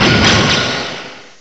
cry_not_magearna.aif